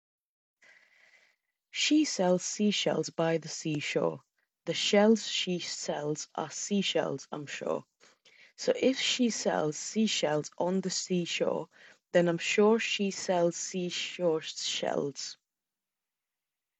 These are your ‘s’ and ‘sh’ sounds which are prone to whistling (and can be unpleasant to listen to), so the best way to do this was by trying a famous tongue twister. As you can hear in the clip above, the mic handled the hissing sounds quite well, and again, the sounds don’t bleed into one another.
Jabra Evolve3 85 — Sibilance.mp3